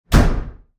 Звуки стука по столу
На этой странице собраны различные звуки стука по столу – от резких ударов кулаком до легкого постукивания пальцами.